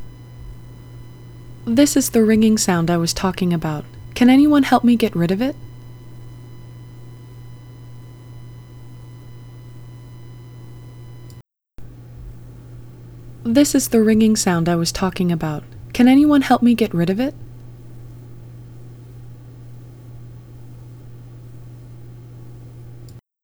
Ringing in the Background of my Recordings
It’s interference : the 1000Hz + harmonics from the computer’s power supply , and in your case an additional +/- 120Hz component from the second harmonic of your mains electricity ( 60Hz) …